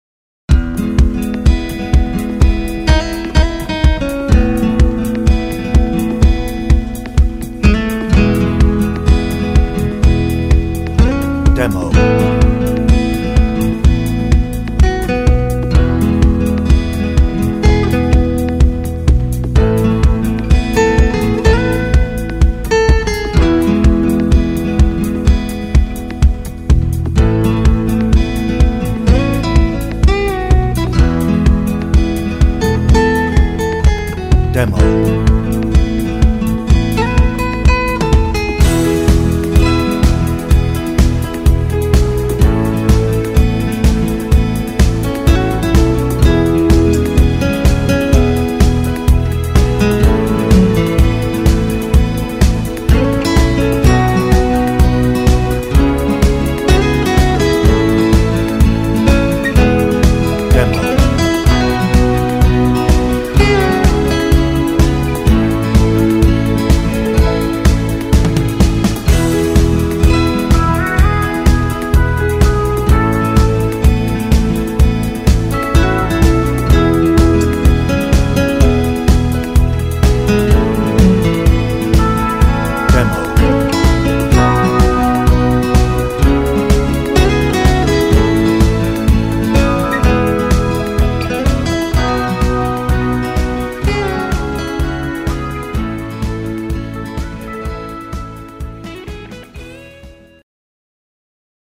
Description Reviews (0) Hoedown - No Ref.
Instrumental